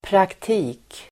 Uttal: [prakt'i:k]